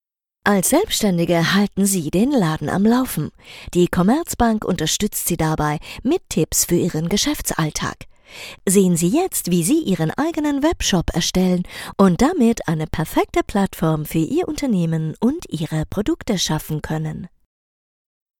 sehr vielseitige, erfahrene Theater und Filmschauspielerin deutsch österreichisches deutsch
wienerisch
Sprechprobe: Industrie (Muttersprache):
very experienced actress and voice actress stage-tv-movie-microfone